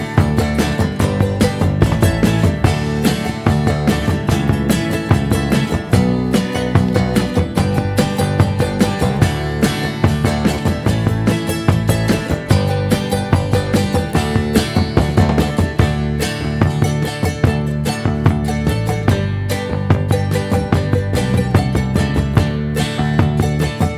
No Backing Vocals Or Kazoo Pop (1970s) 3:43 Buy £1.50